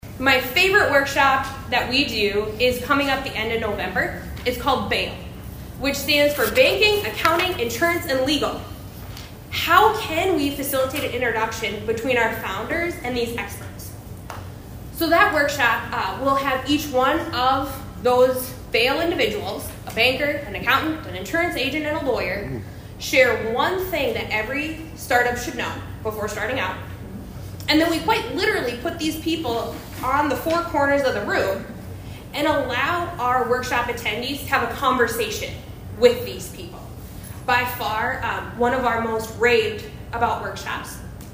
ABERDEEN, S.D.(HubCityRadio)- The Aberdeen Chamber of Commerce’s Chamber Connections Series continued Thursday at the K.O.Lee Public Library.